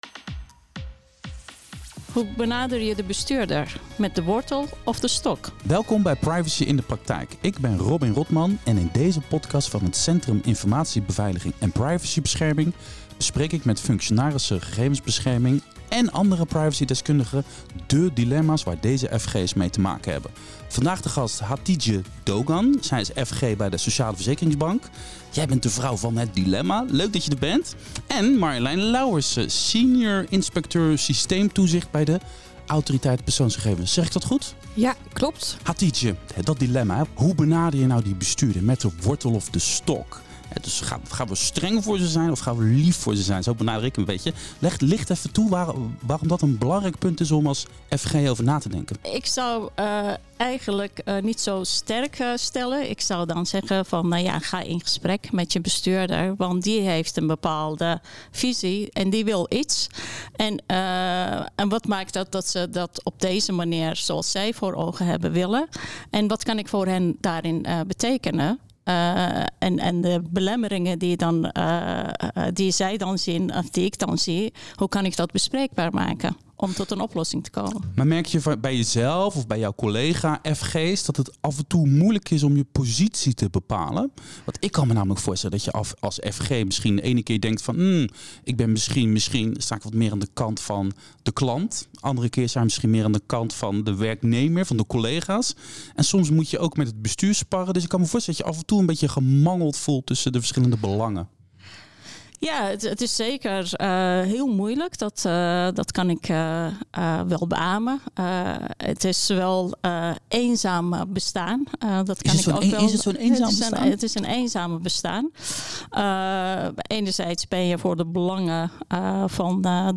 LEVENDIGE MUZIEK